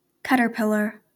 Caterpillars (/ˈkætərpɪlər/
KAT-ər-pil-ər) are the larval stage of members of the order Lepidoptera (the insect order comprising butterflies and moths).